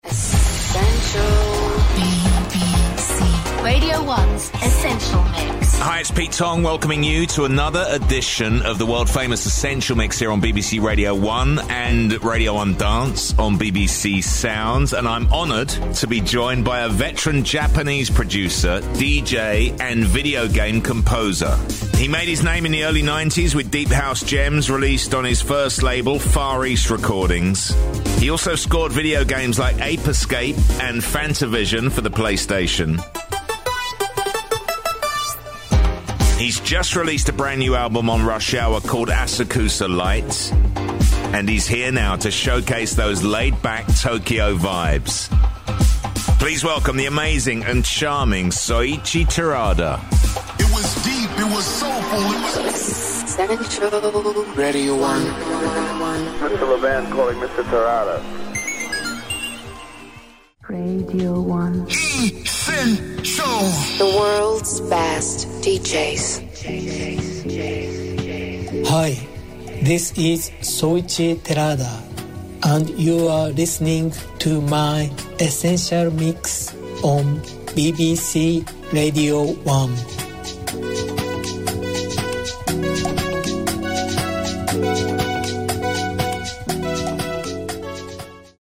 和製ディープハウス／ジャパニーズハウス